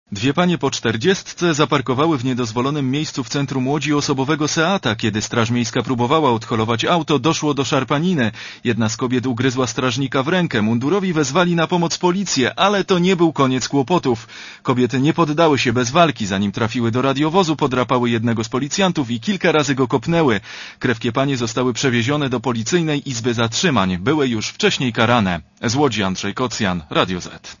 Posłuchaj relacji reportera Radia ZET (107kB)